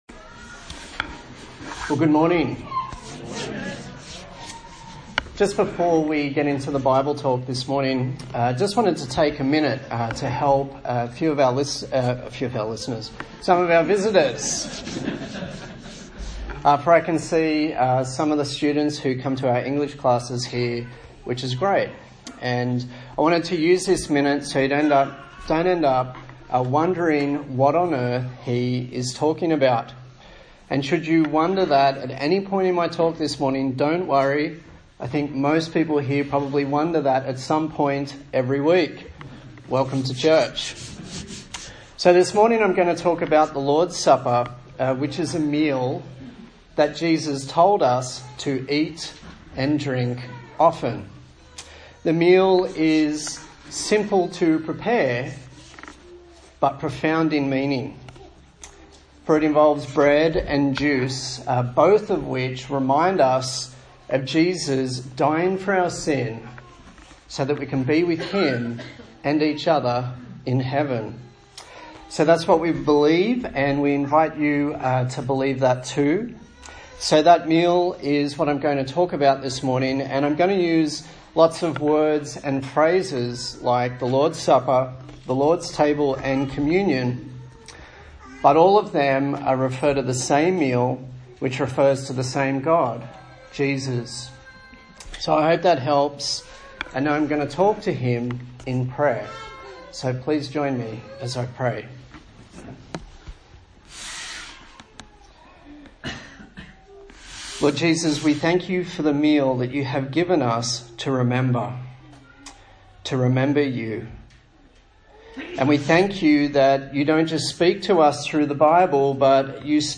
A sermon on the book of 1 Corinthians
Service Type: Sunday Morning